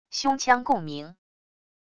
胸腔共鸣wav音频